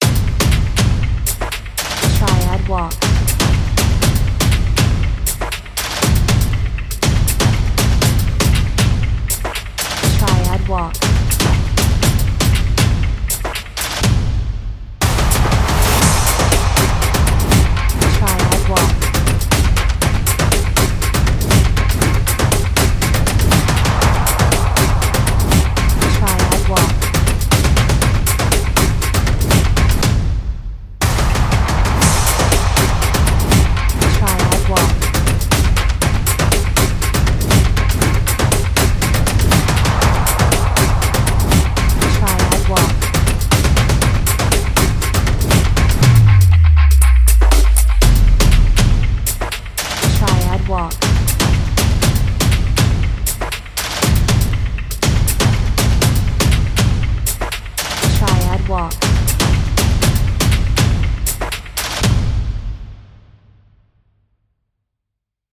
近未来的 , 舞台 , 緊張感 , 疾走感 , スリラー , アグレッシブ , World music